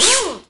VEC3 Percussion 090.wav